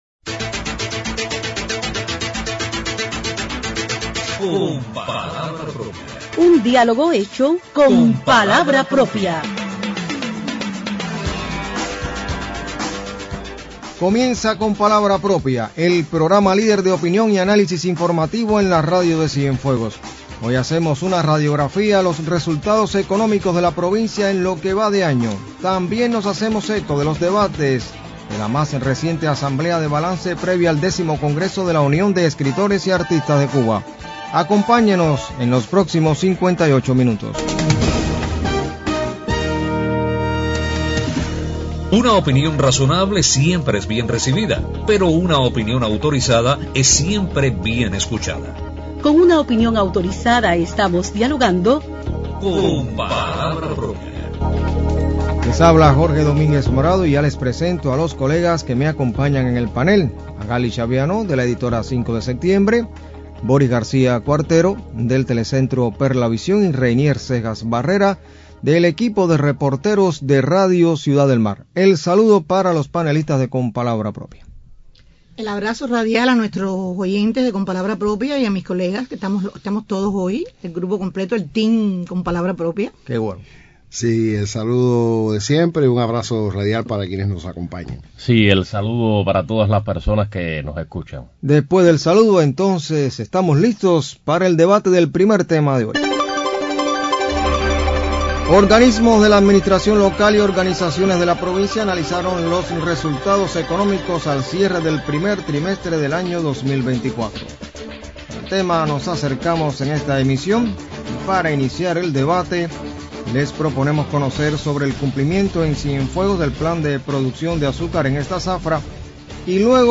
Acerca de los debates de la más reciente asamblea de balance de la Unión de Escritores y Artistas de Cuba, correspondiente al proceso preparatorio del Décimo Congreso también comentan los panelistas en este espacio.